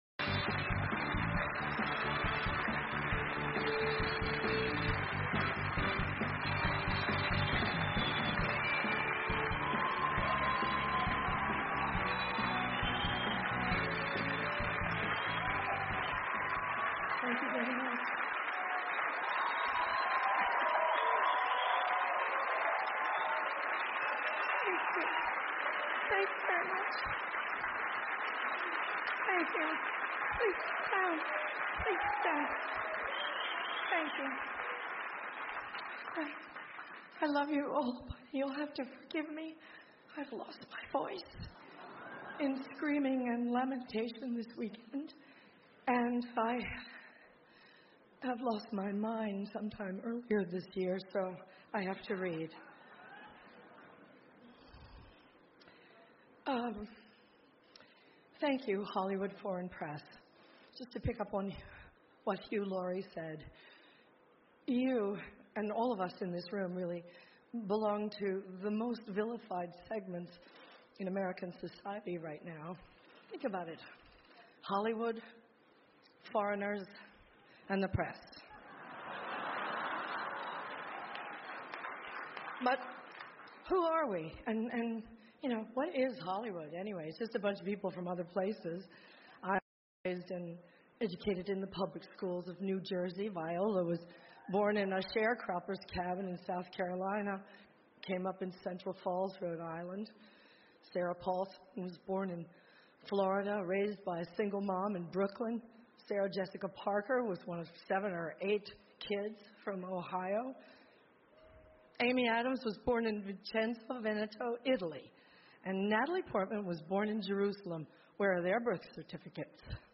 欧美人文风情第268篇:梅姨金球奖得奖感言 炮轰川普 听力文件下载—在线英语听力室